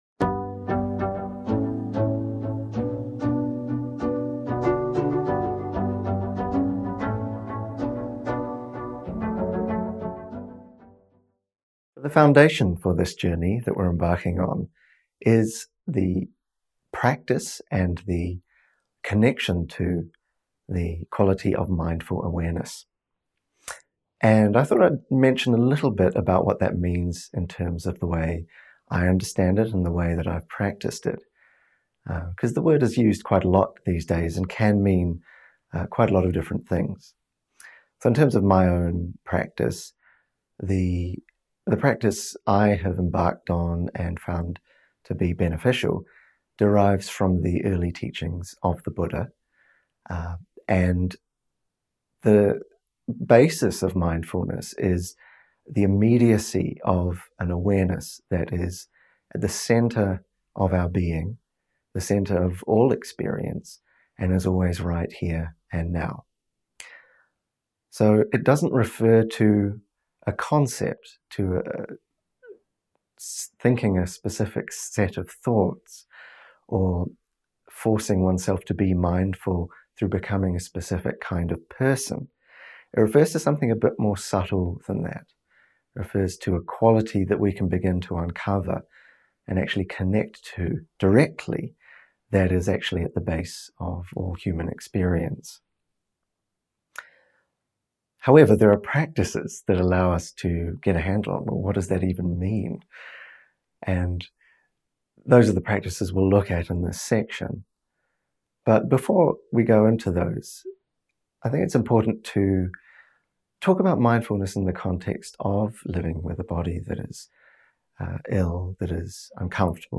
Talk: Mindful Awareness as Our Refuge